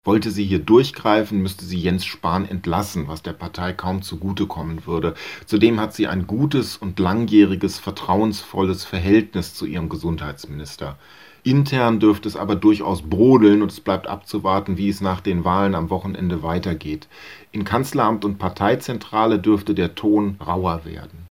Politikwissenschaftler